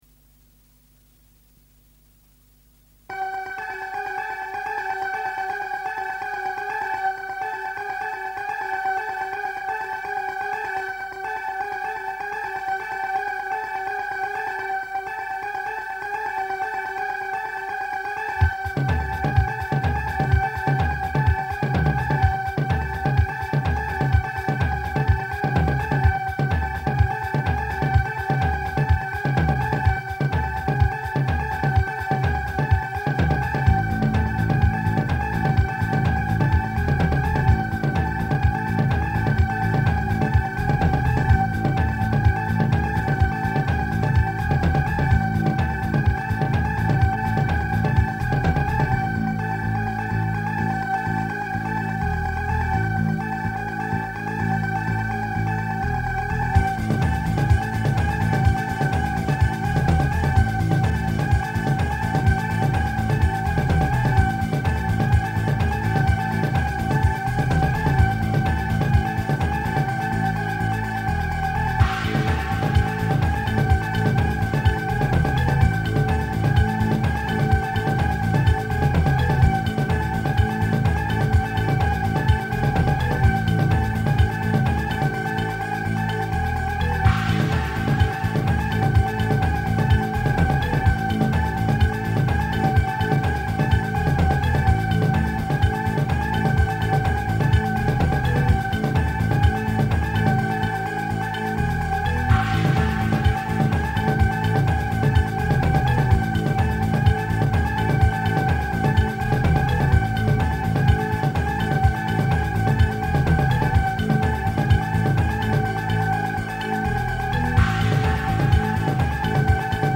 Tags: nightmares dj mixes rock n roll